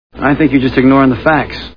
Red Planet Movie Sound Bites